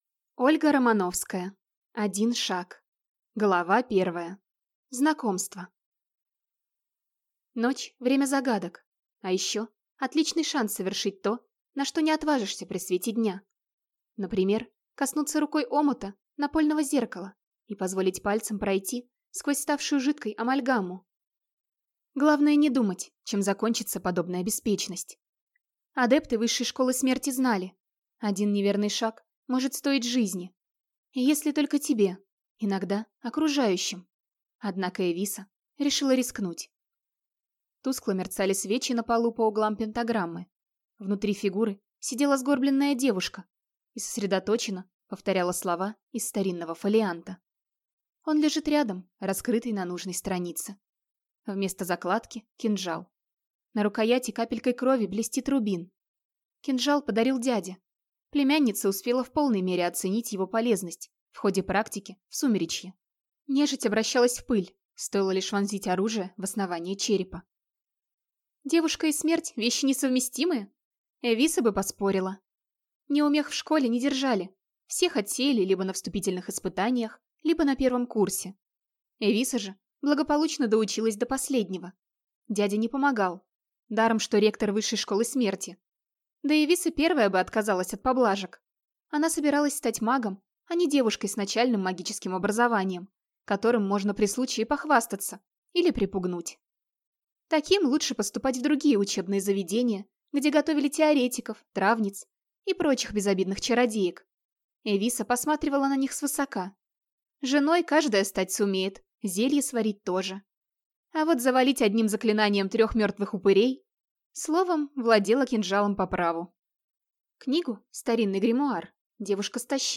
Аудиокнига Один шаг | Библиотека аудиокниг
Прослушать и бесплатно скачать фрагмент аудиокниги